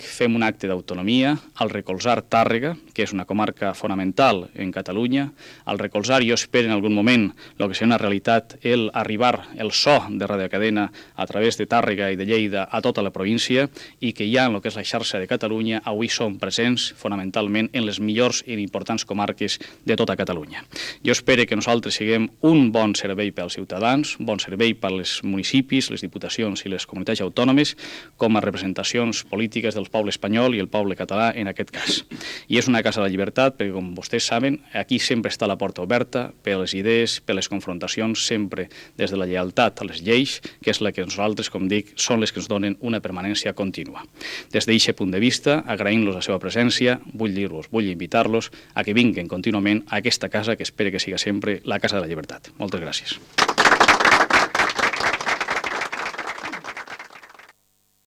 Paraules del director de Radiocadena Española Jordi García Candau el dia de la inauguració dels nous estudis de RCE Tàrrega, al carrer Santa Anna